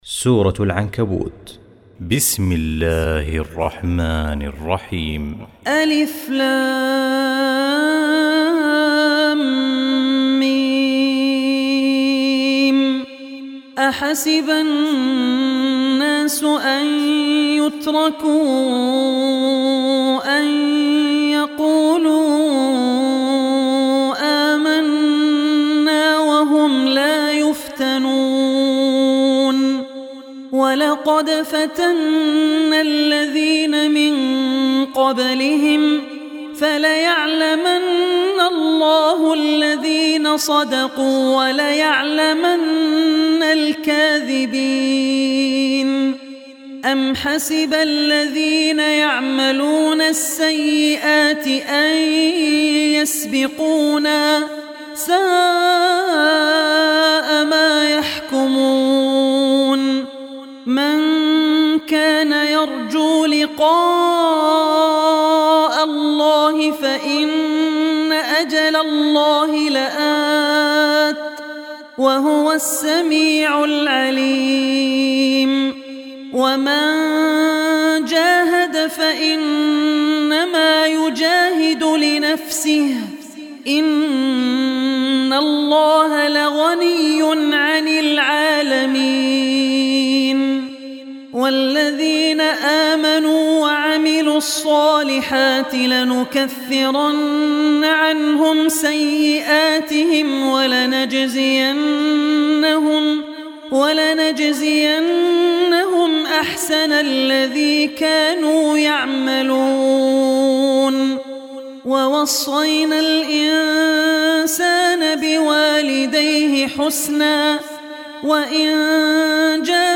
quran tilawat